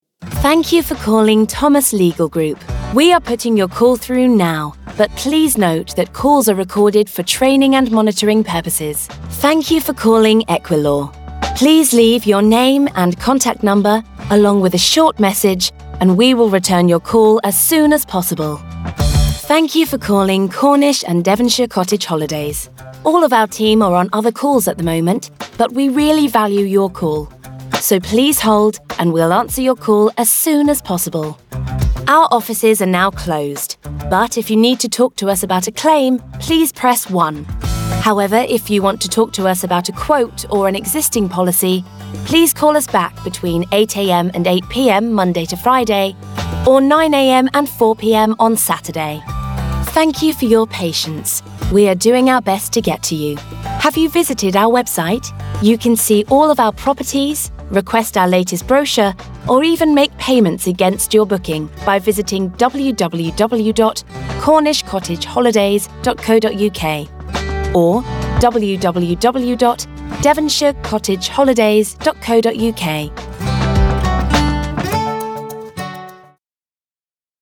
English Female Voice Overs for On Hold Messaging
Accent: RP. Neutral English
Tone / Style: Upbeat, warm, informative but friendly, clear, fun, pleasing